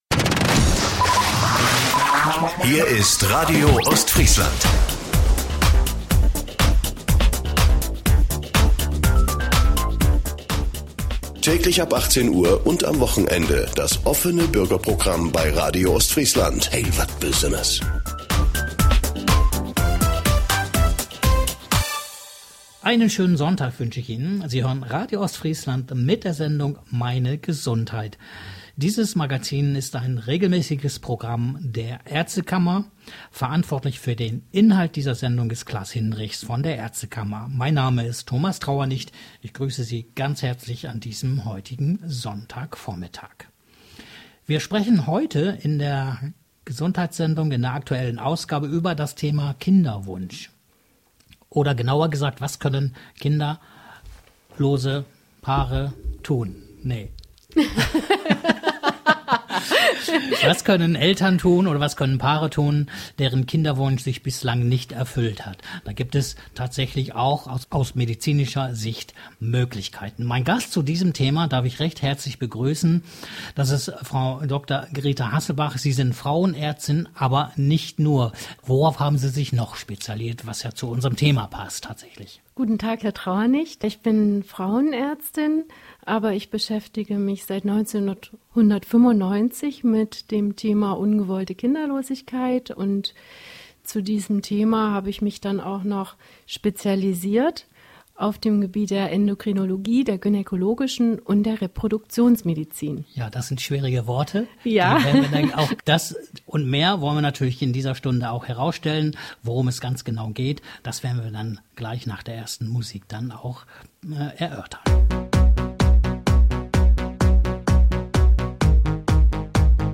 Interview Radio – Meine Gesundheit
Sendung / Interview vom 28. Juni 2020 im Radio Ostfriesland „Meine Gesundheit“.